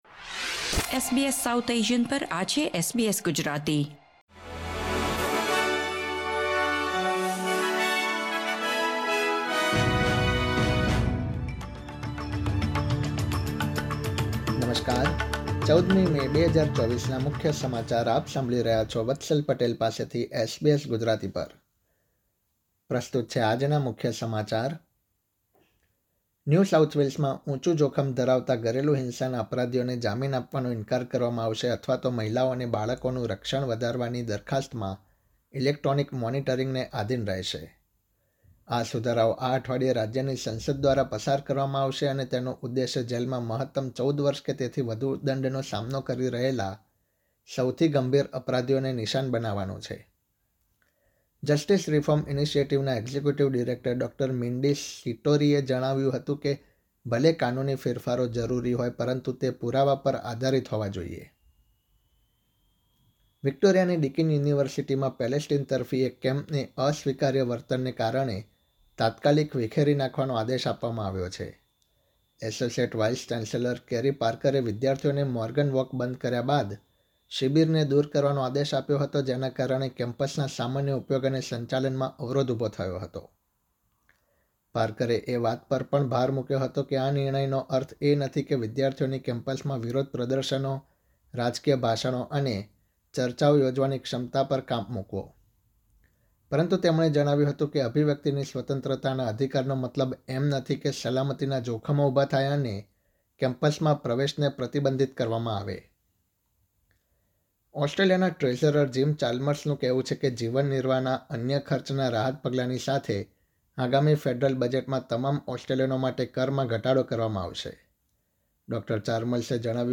SBS Gujarati News Bulletin 14 May 2024